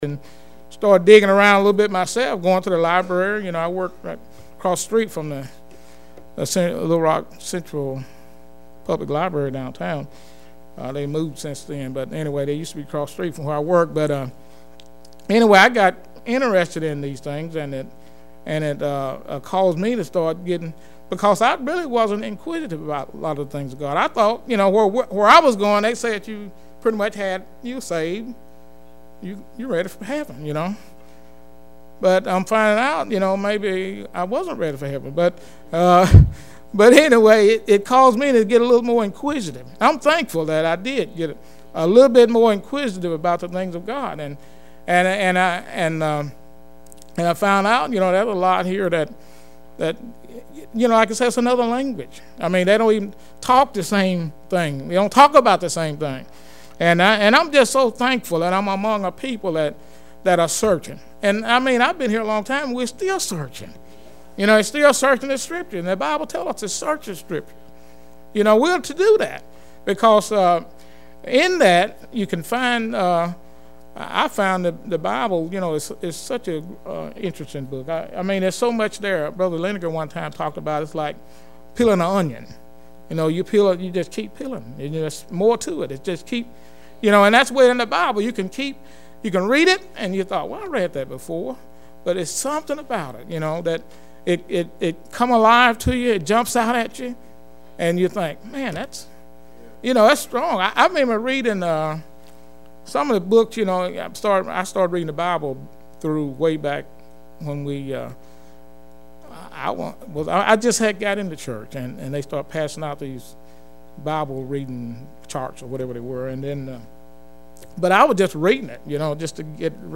2/24/10 Wednesday Service